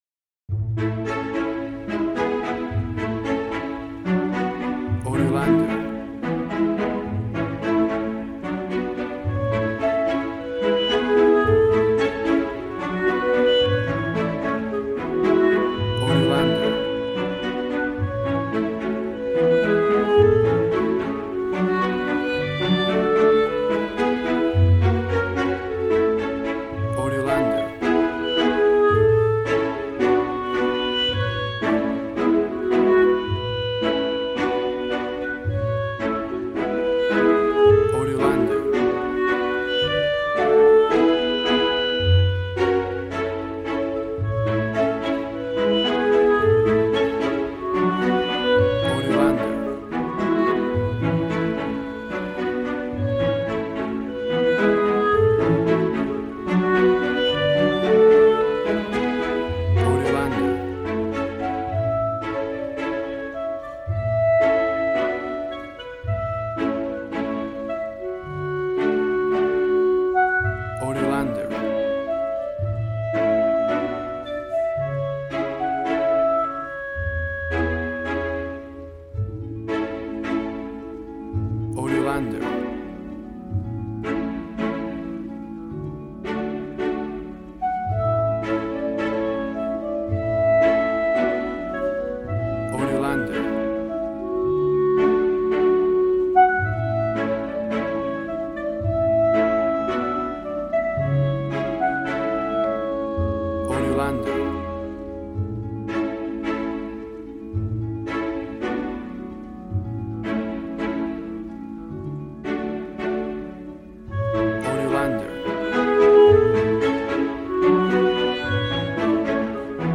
A warm and stunning piece of playful classical music.
Regal and romantic, a classy piece of classical music.
Tempo (BPM): 109